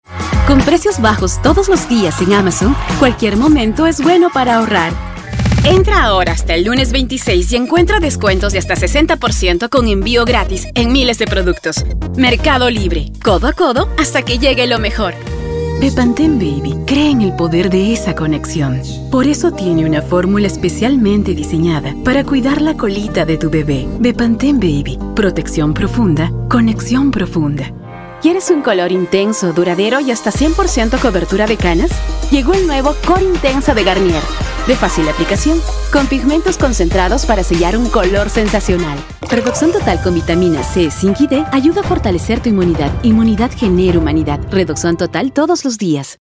Female
Approachable, Assured, Authoritative, Confident, Conversational, Cool, Corporate, Engaging, Friendly, Natural, Reassuring, Smooth, Soft, Upbeat, Versatile, Warm
Peruvian (native)
Audio equipment: Apollo x6, Soundproof and acoustically treated recording booth